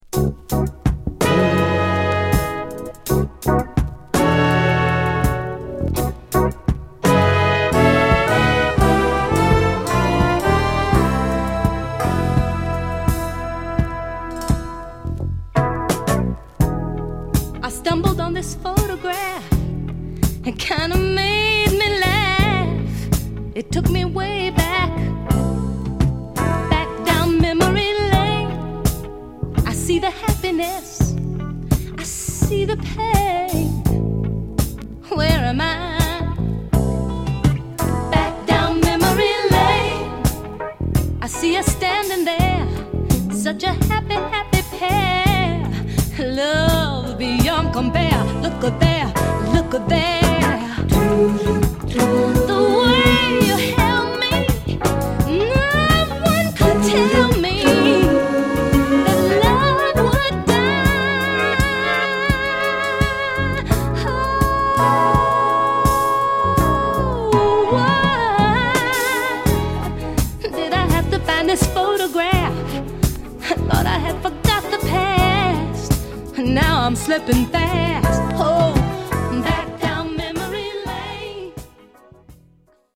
グルーヴィーなベース・ラインや幻想的なストリングス/シンセが織りなす緩やかなトラックに
文句無しのメロウ・グルーヴ・クラシック！
(Stereo)